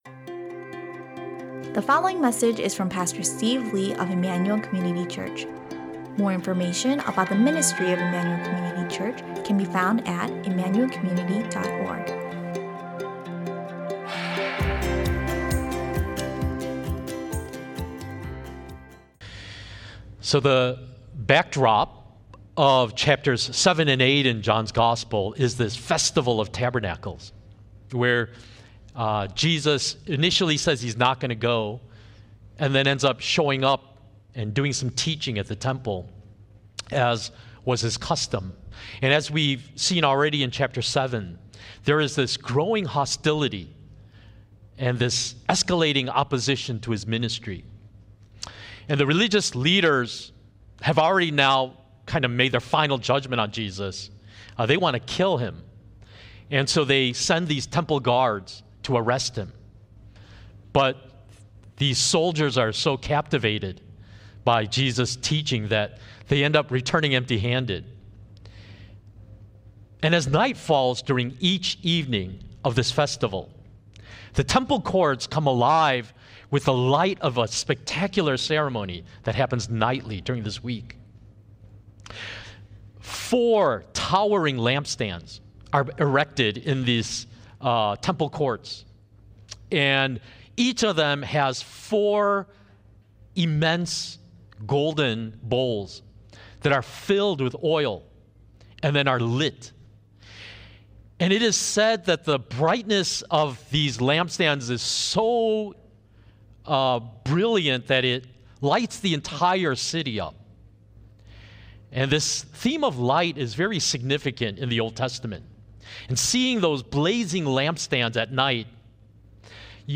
Sunday Service “Light of the World” – John 8:12-59 | Immanuel Community Church